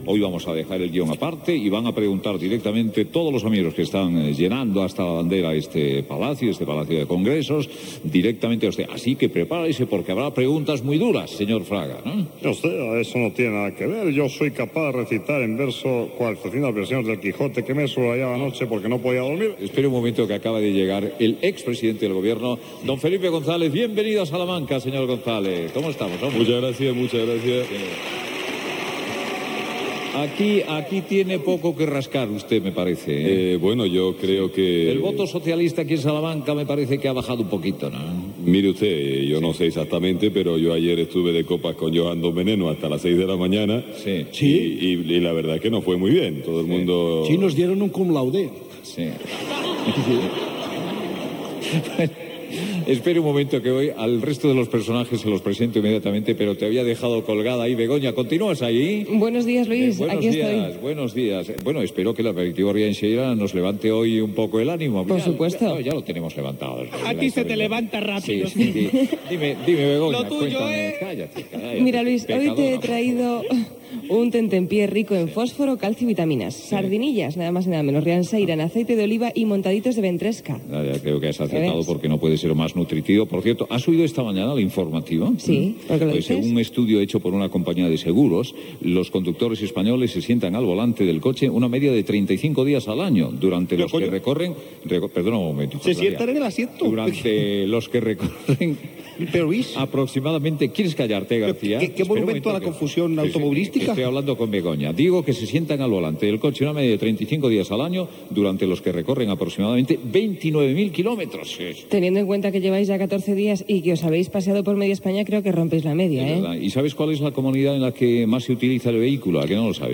Programa fet cara al públic des de Salamanca. Secció "El jardín de los bonsais", amb la menció publicitària de "Rianxeira" i l'explicació de l'arribada del Rallye "Protagonistas" a la ciutat. (Amb les imitacions de Felipe González, Cristina Almeida, José María García, José María Ruiz Mateos, etc.)
Info-entreteniment